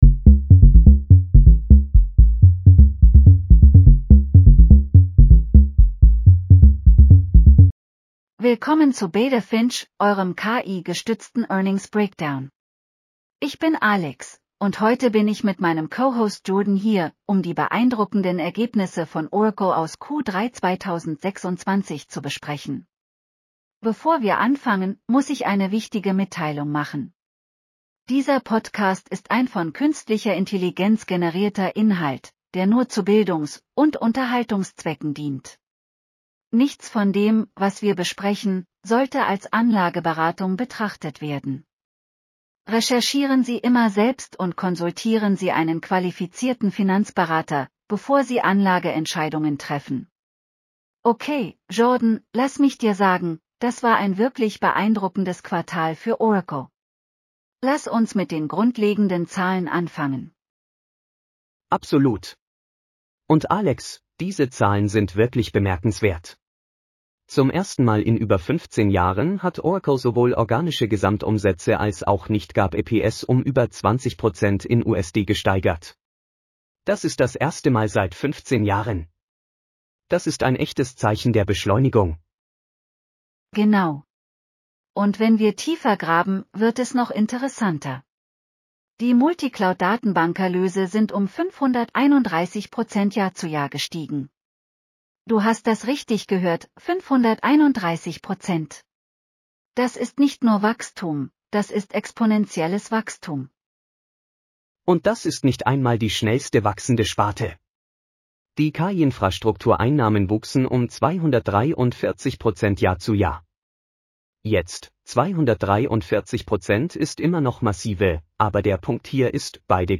AI-powered earnings call analysis for Oracle (ORCL) Q3 2026 in Deutsch.